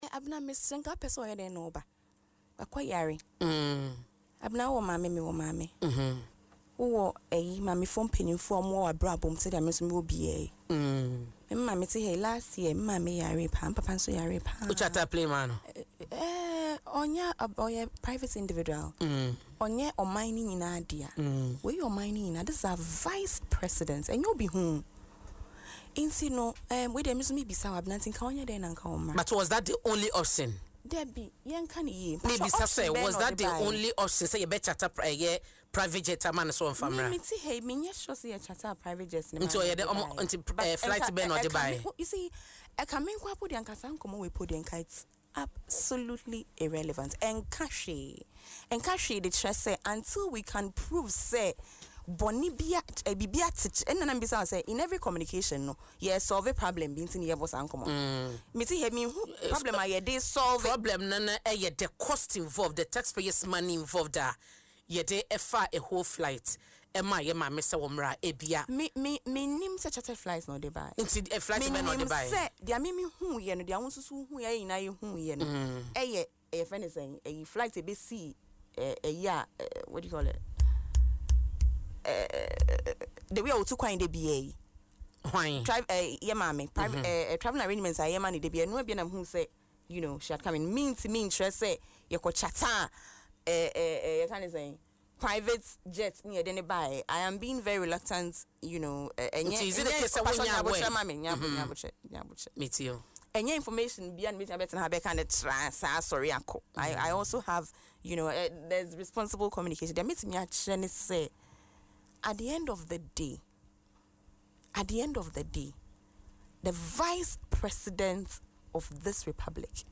Speaking on Adom FM’s Dwaso Nsem show